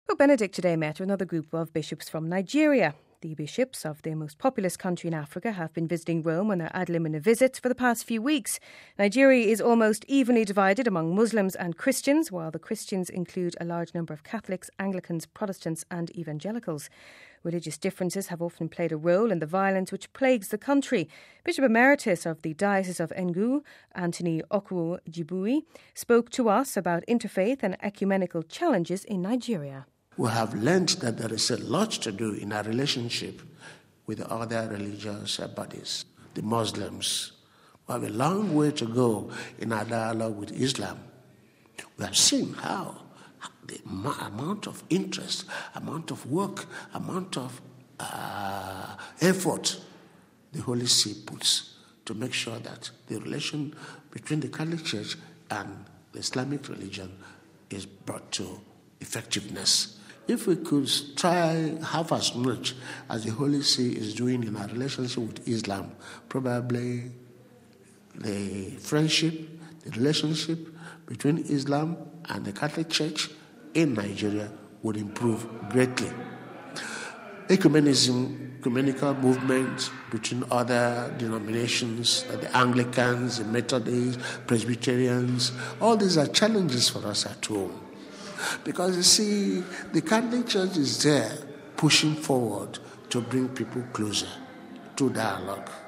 Religious differences have often played a role in the violence which plagues the country. Bishop Emeritus of the Diocese of Enugu, Anthony Okonkwo Gbuji, spoke to us about interfaith and ecumenical challenges in Nigeria.